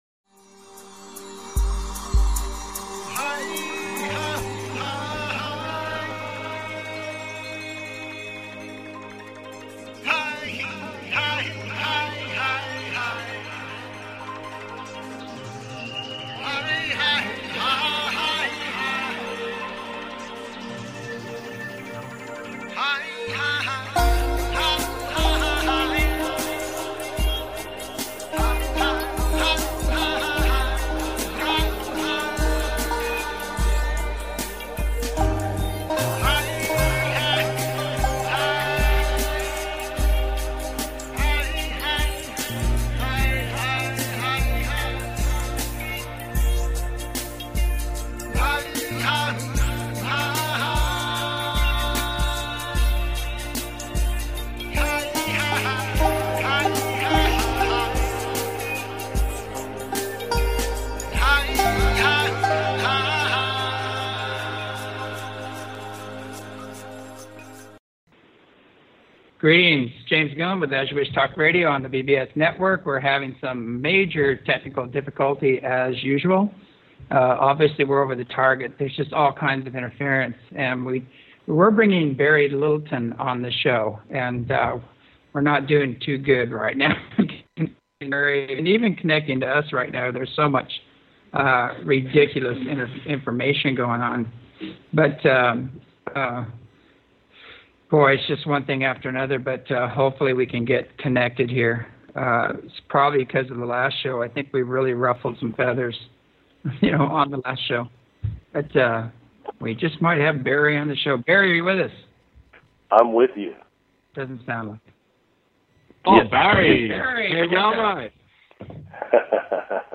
As You Wish Talk Radio